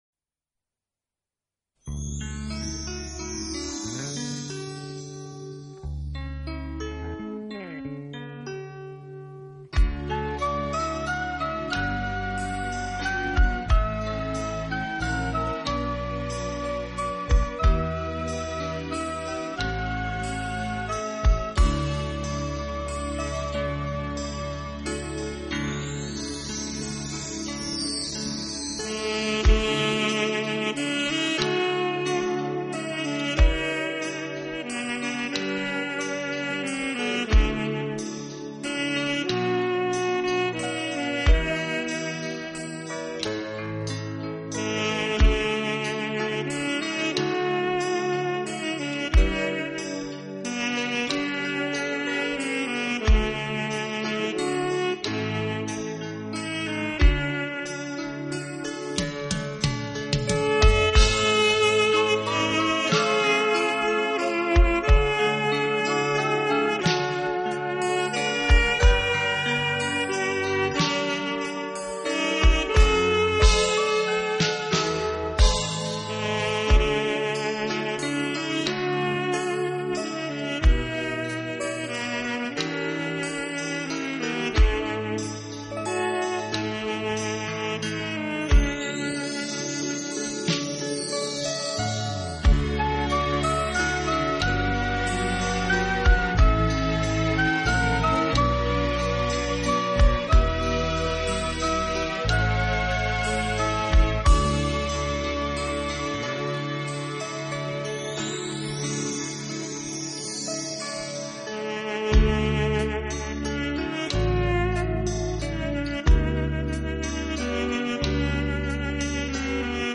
音乐流派： Instrumental
纯器乐专集，许多经典老歌的萨克斯演绎，让人流连忘返……